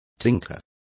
Complete with pronunciation of the translation of tinker.